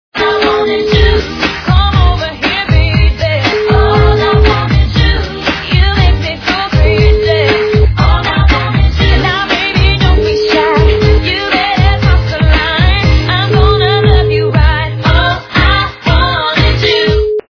- западная эстрада
При заказе вы получаете реалтон без искажений.